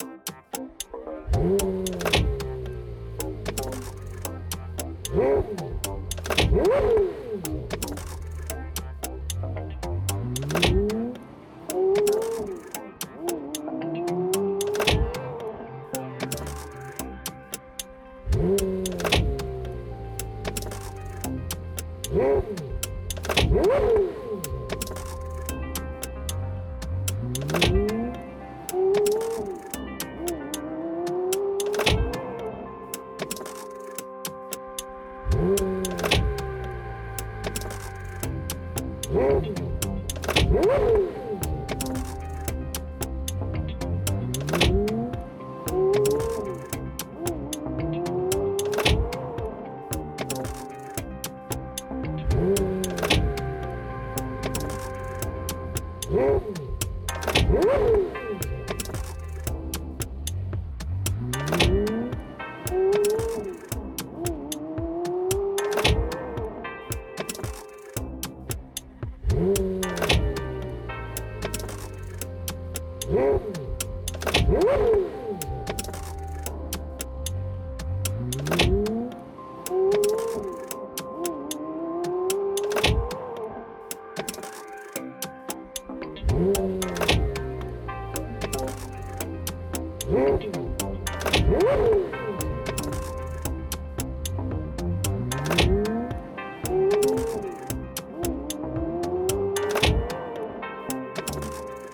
Aber meine Lieblingsteils war die 10-Meter-lange Bildschirm, wo Man unterschiedliche Teile der Autos ein Lied zu machen benutzen kann.